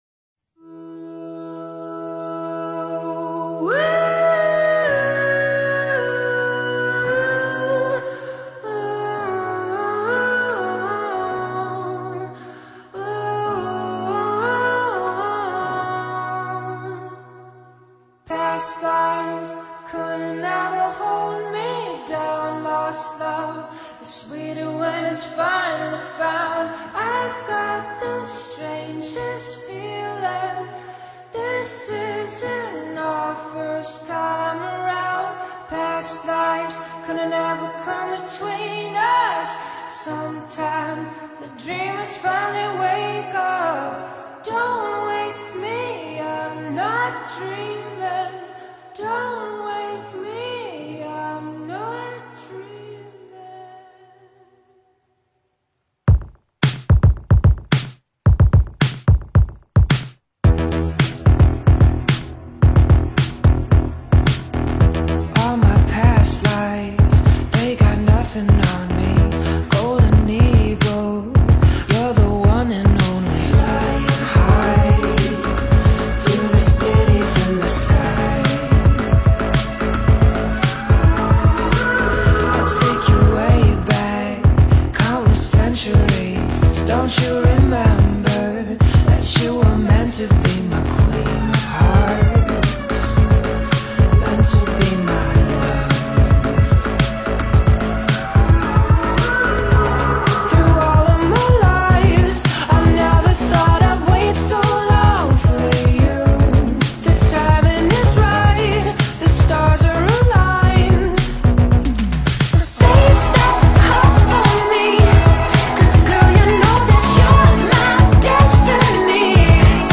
ورژن اصلی موزیک